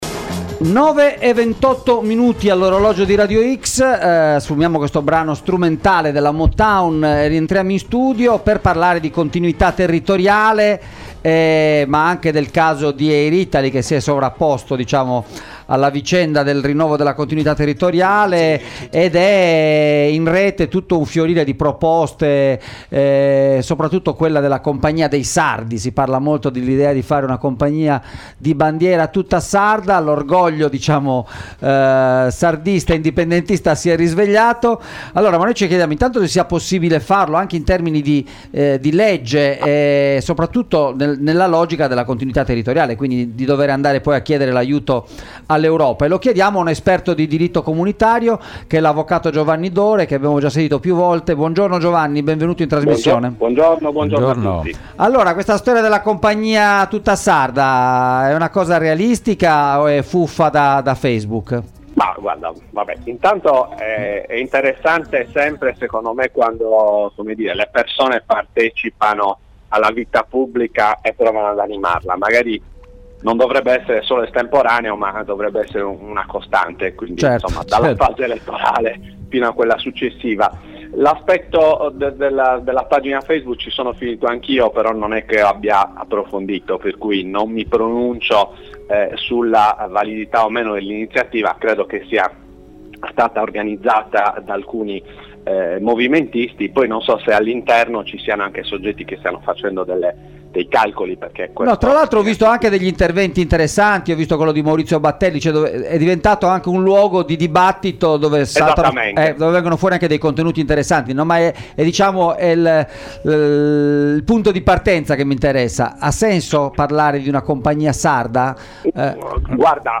Compagnia aerea sarda: si può fare? – intervista